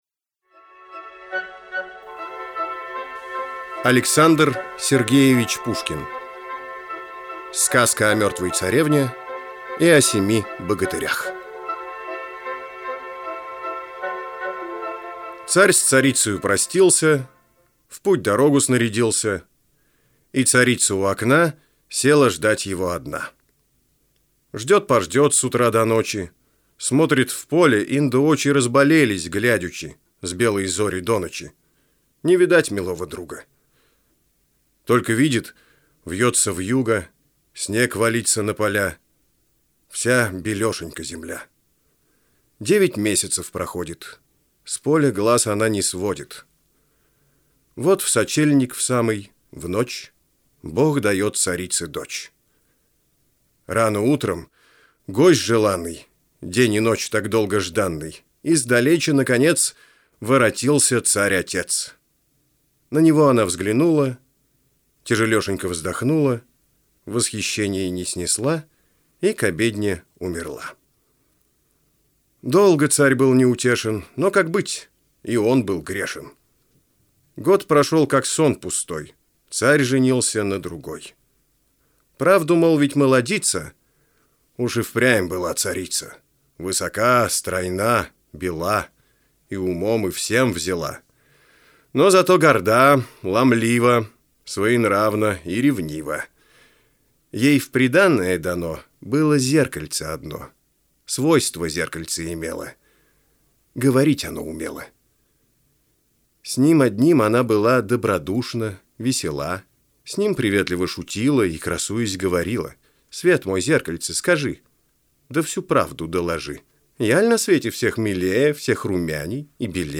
Аудиосказки для детей и взрослых